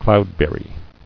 [cloud·ber·ry]